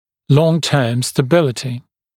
[‘lɔŋˌtɜːm stə’bɪlətɪ][‘лонˌтё:м стэ’билэти]долгосрочная стабильность